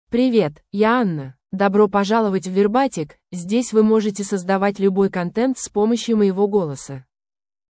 Anna — Female Russian AI voice
Anna is a female AI voice for Russian (Russia).
Voice sample
Listen to Anna's female Russian voice.
Anna delivers clear pronunciation with authentic Russia Russian intonation, making your content sound professionally produced.